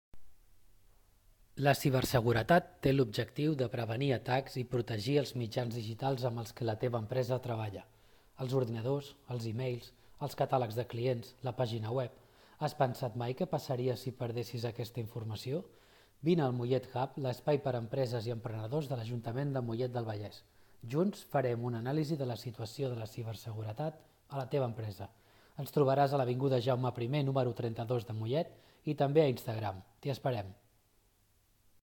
Cada un d’ells ens ha gravat un breu missatge per poder emetre al final del “Posa’t les piles” de Ràdio Mollet:
Veu 3: